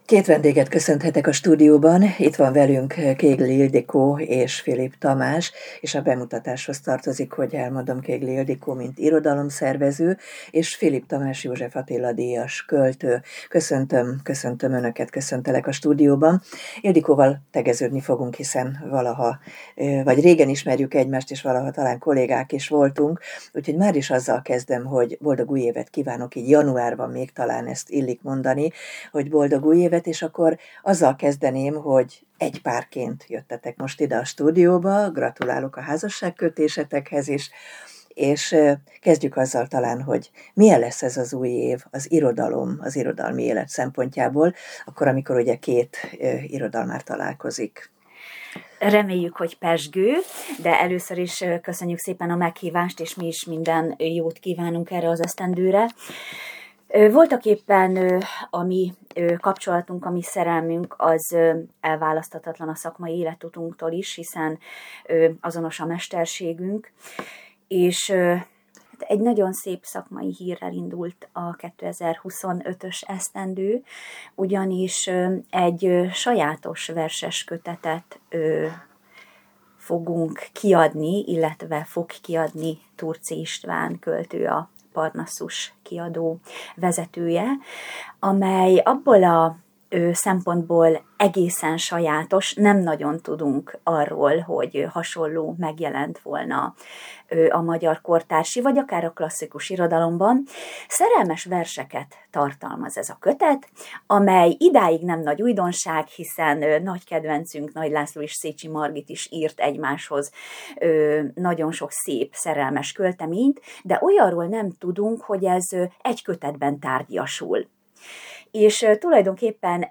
A versekről, a kortárs irodalomról beszélgettünk, és arról hogy megszületett közös verses kötetük kézirata, amit a költészet napján a verskedvelők is kezükbe vehetnek.